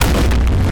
laserbig.ogg